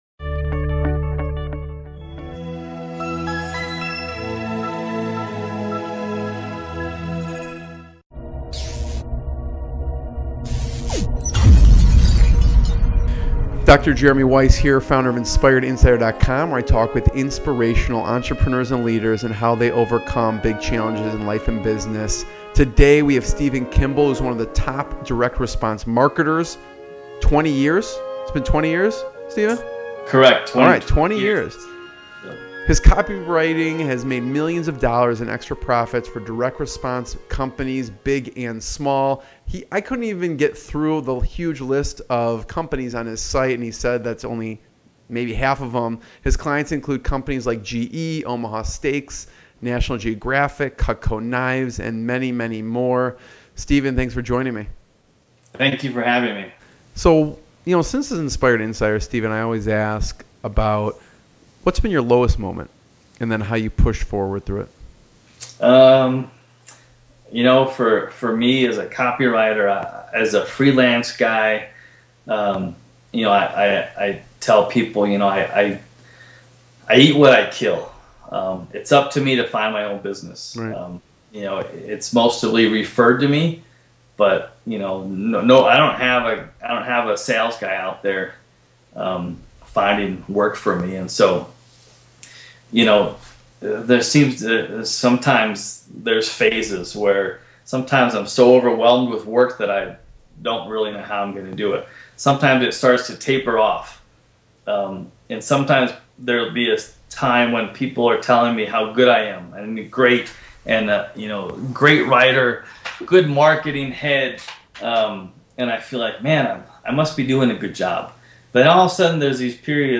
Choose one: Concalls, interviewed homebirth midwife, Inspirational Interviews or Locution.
Inspirational Interviews